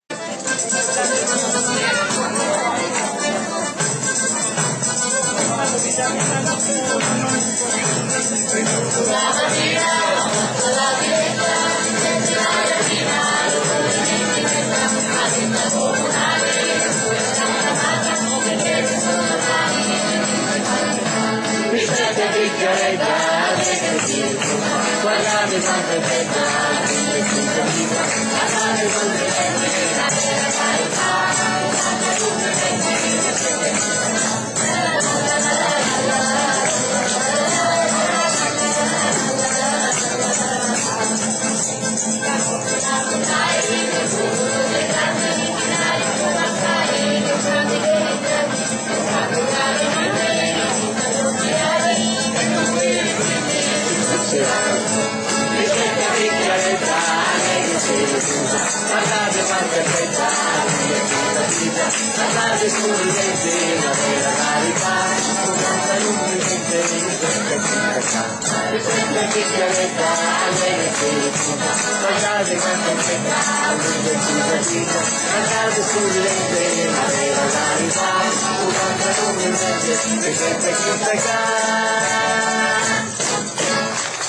Mistretta vecchia bedda (cantata dal gruppo I cantori amastratini - Testo).
Mistretta vecchia bedda cantata dal gruppo I canterini amastratini.mp3